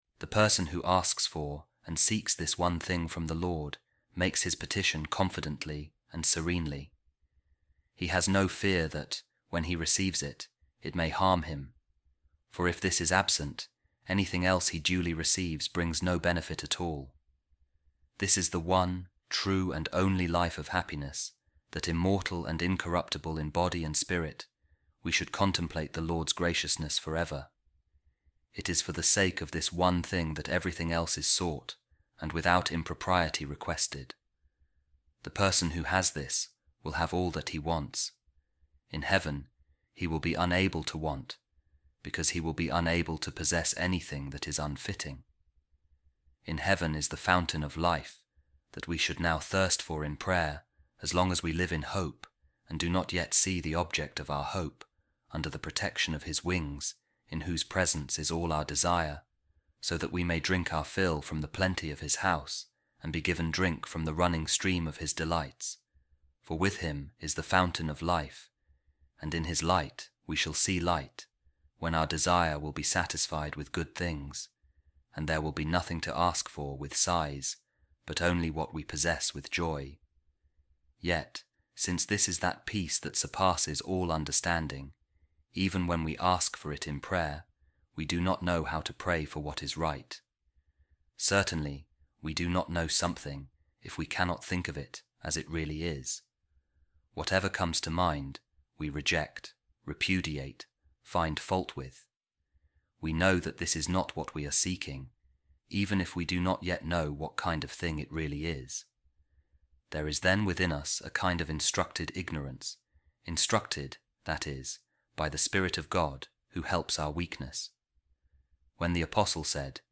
A Reading From The Letter Of Saint Augustine To Proba | The Spirit Intercedes For Us